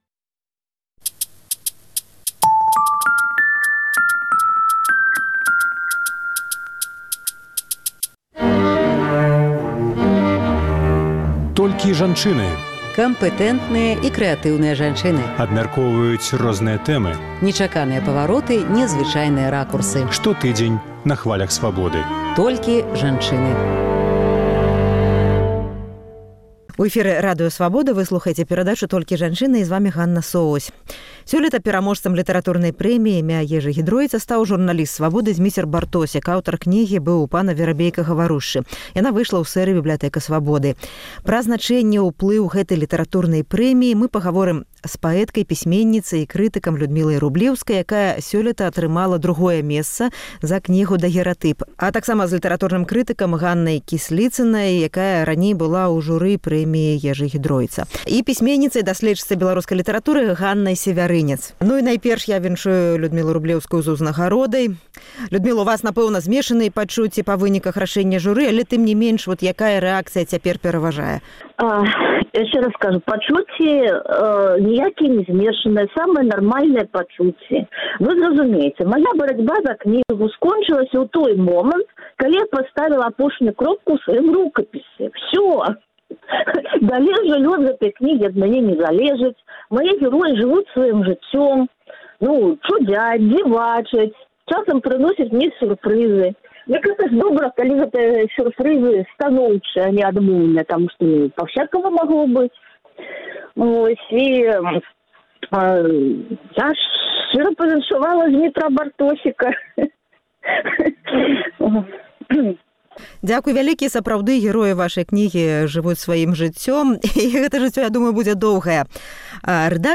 Толькі жанчыны» гаворым з паэткай, пісьменьніцай і крытыкам